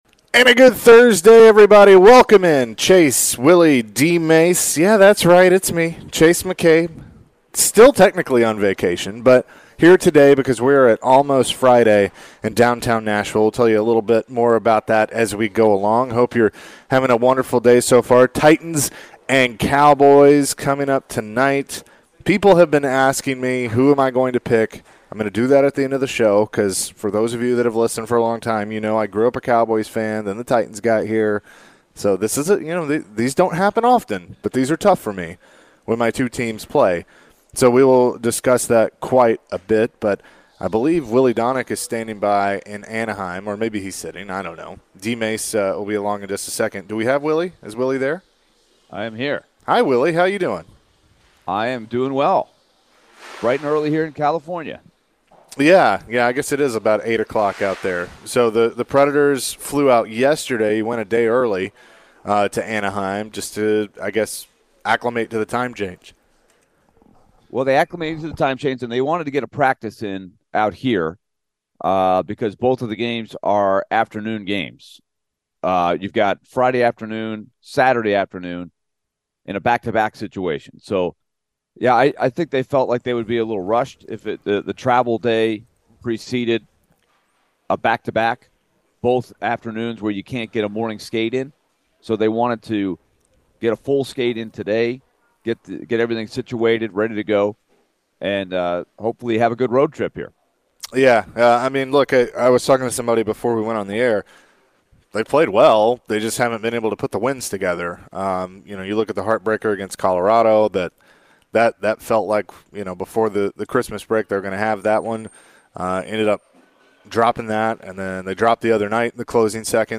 Hour 1 Live form Almost Friday (12-29-22)